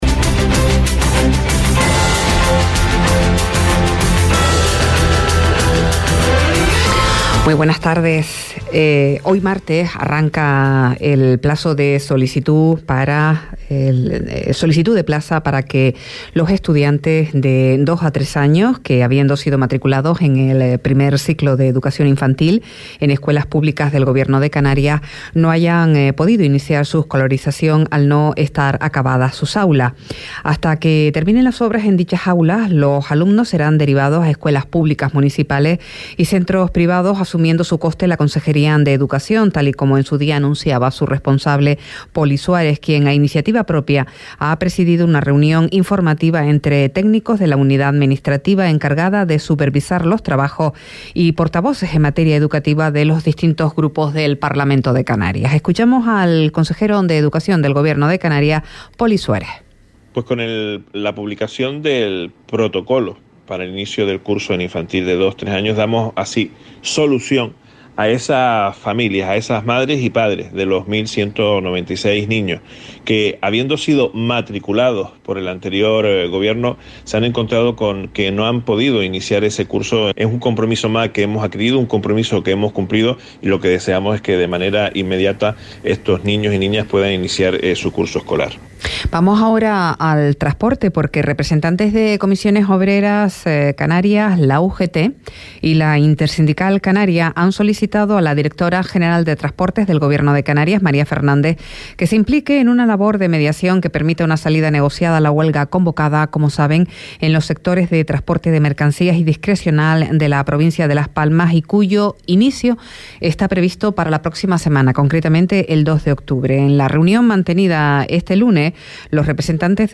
Informativos en Radio Sintonía - 26.09.23